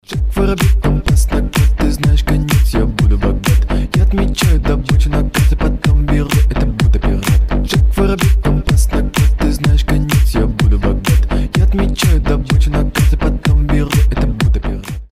Рингтоны ремиксы
Басы , Club house